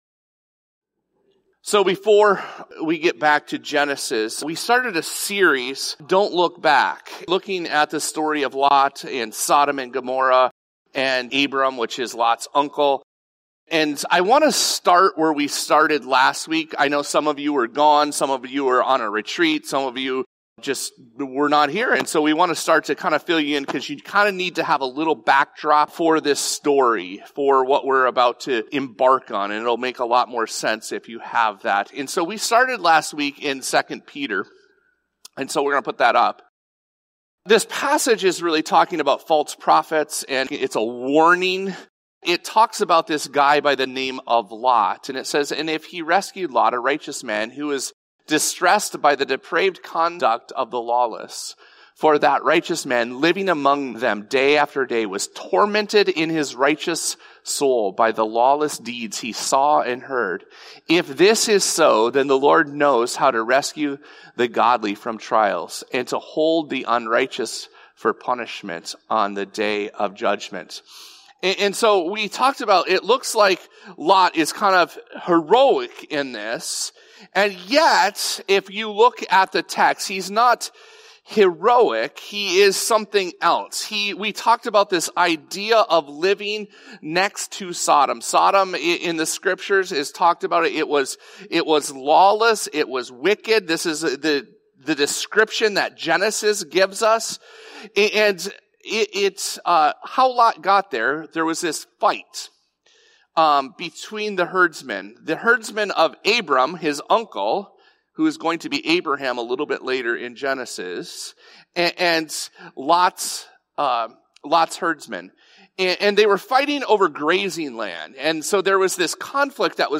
This episode of the Evangel Houghton podcast is a Sunday message from Evangel Community Church, Houghton, Michigan, January 25, 2026.